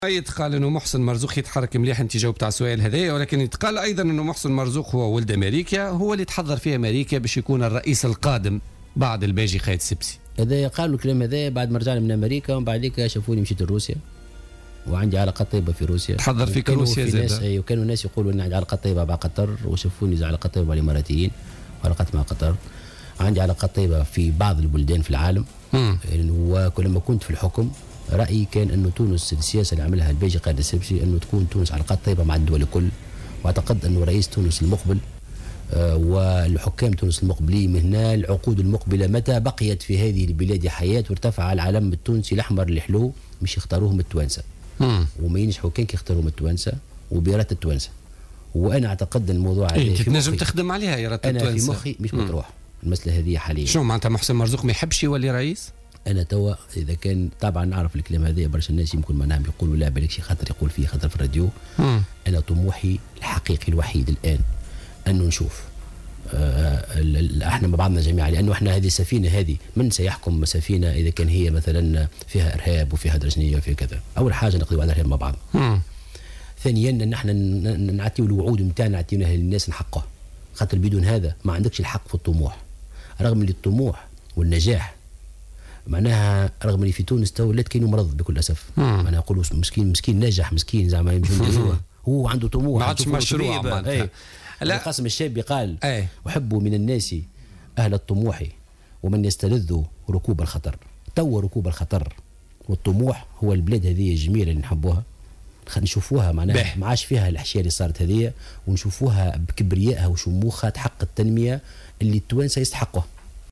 Le secrétaire général de Nidaa Tounes, Mohsen Marzouk, était l’invité de Jawhara FM ce mercredi 8 juillet 2015 dans le cadre de l’émission Politica.